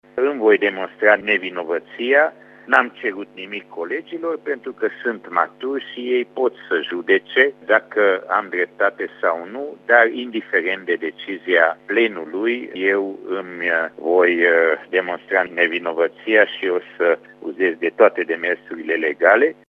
Deputatul UDMR îşi susţine nevinovăţia, revine Borbély Lászlo: